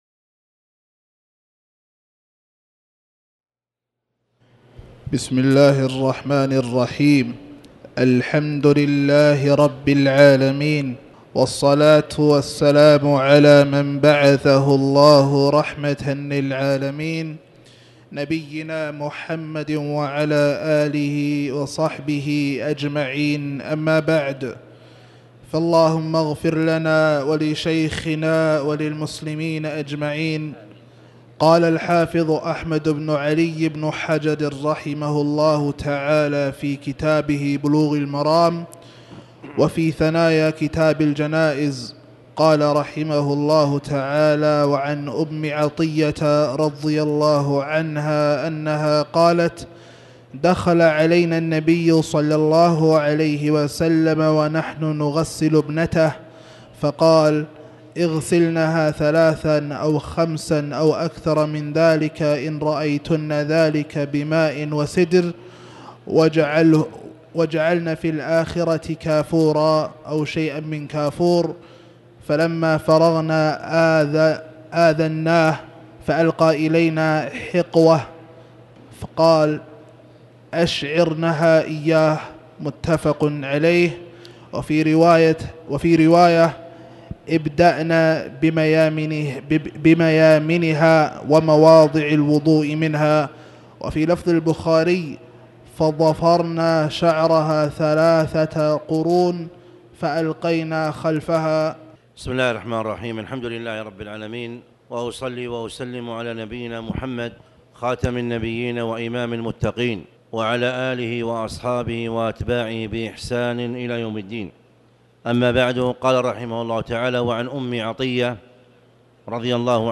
تاريخ النشر ٢٩ جمادى الأولى ١٤٣٩ هـ المكان: المسجد الحرام الشيخ
29jmada-alawla-shrh-slah-aljnayz-bad-alfjr.mp3